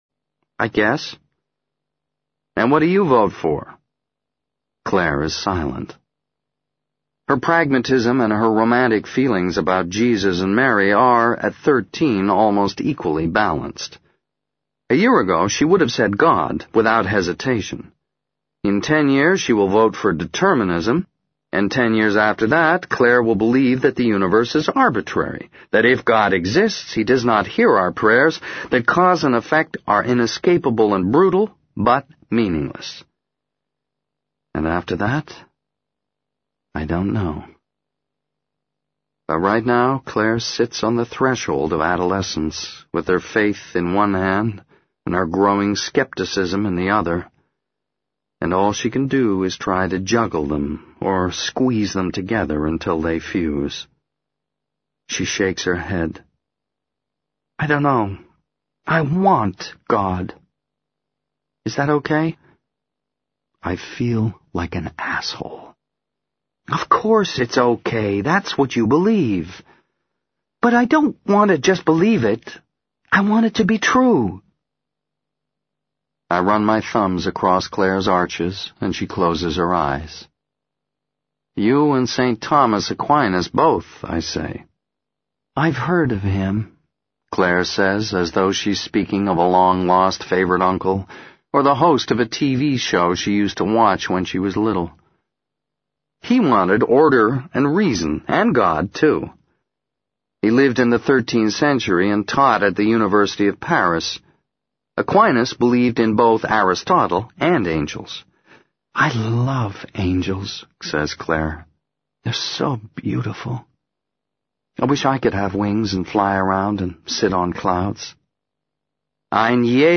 在线英语听力室【时间旅行者的妻子】67的听力文件下载,时间旅行者的妻子—双语有声读物—英语听力—听力教程—在线英语听力室